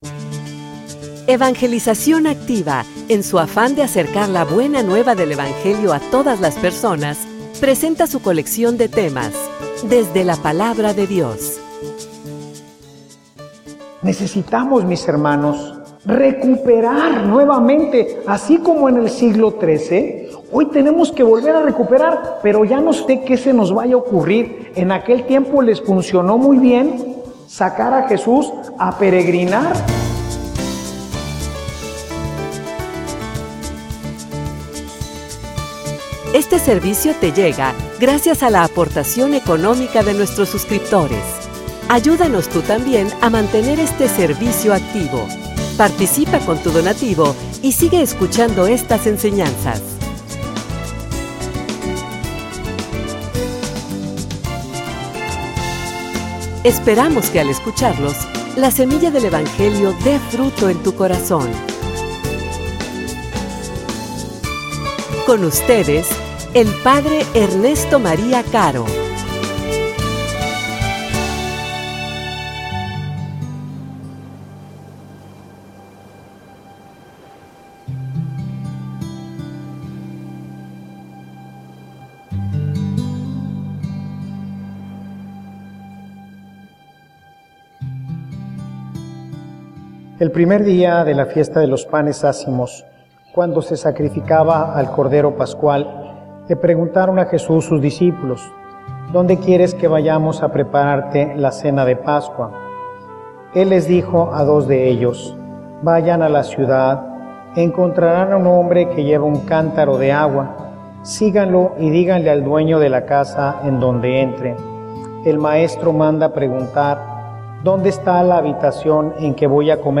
homilia_No_te_acostumbres_a_lo_sagrado.mp3